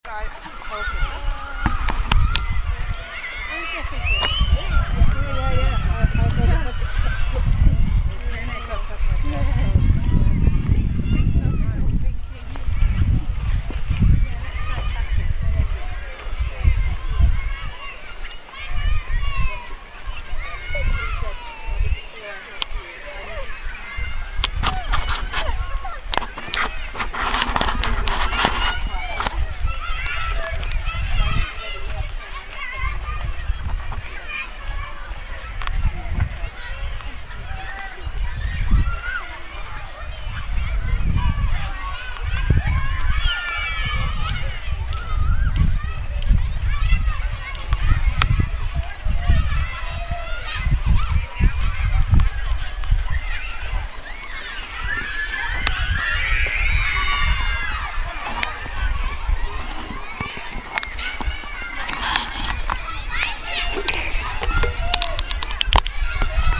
A walkthrough of the school in one of the world's largest shanty towns, Mukuru in Nairobi.